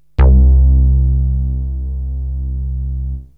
SYNTH BASS-2 0013.wav